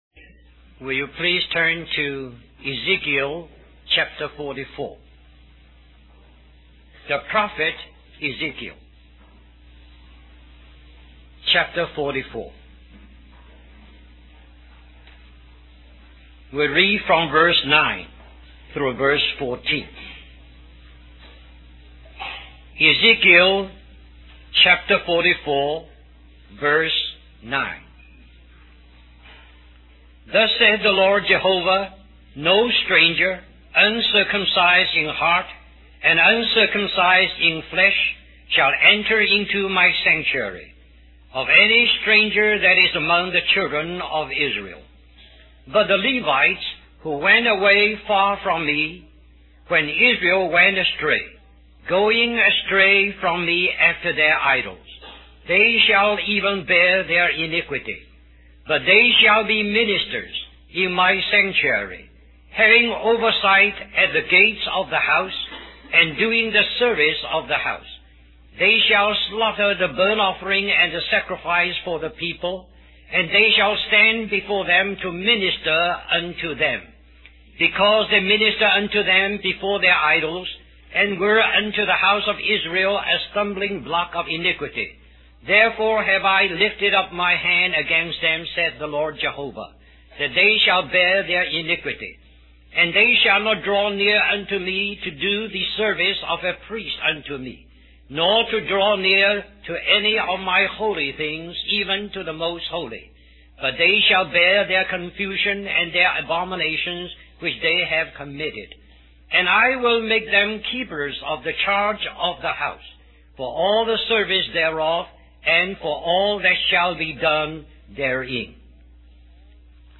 1990 Harvey Cedars Conference Stream or download mp3 Summary This message is also printed in booklet form under the title: Spiritual Ministry.